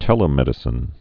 (tĕlĭ-mĕdĭ-sĭn)